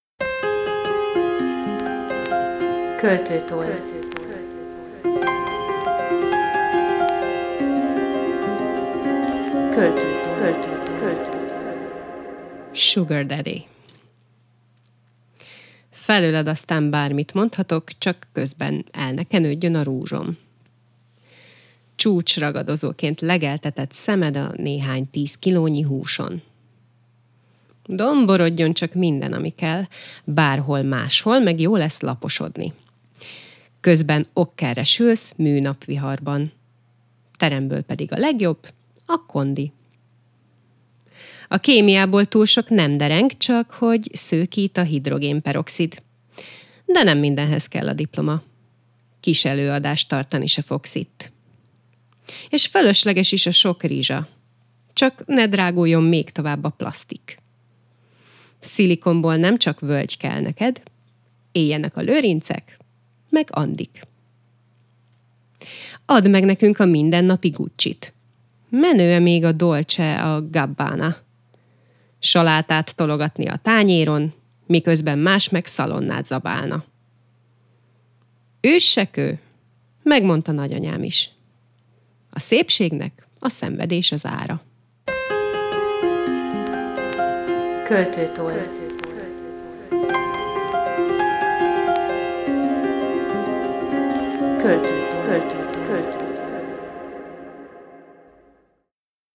Mostani versemben – ami talán inkább egy slam poetryre hajazó szöveg – éppen ezt kíséreltem meg.